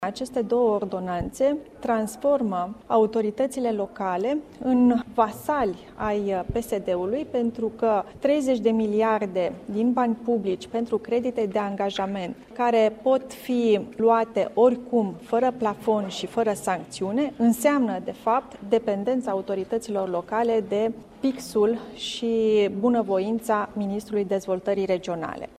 Potrivit preşedintelui interimar al PNL, Raluca Turcan, subiectul acestei audieri va fi emiterea de către Guvern a două acte normative din domeniul economic ( ordonanţele de urgenţă 6 şi 9 ):